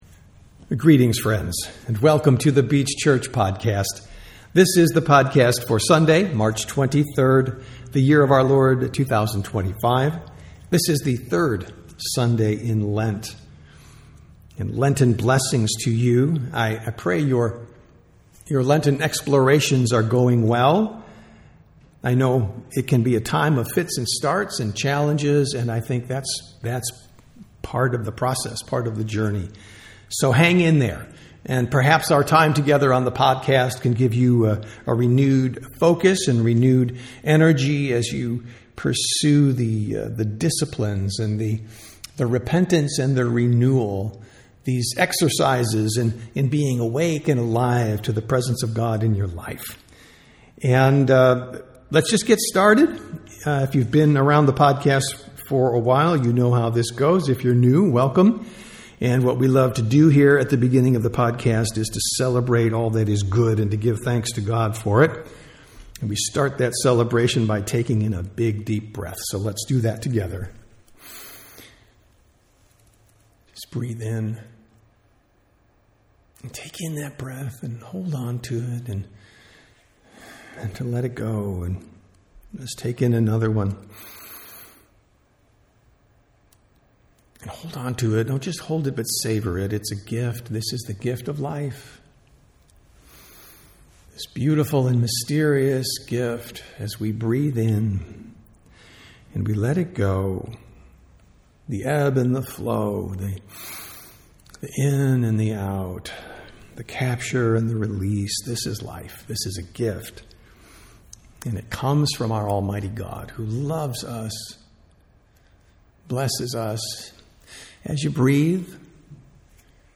Sermons | The Beach Church
Sunday Worship - March 23, 2025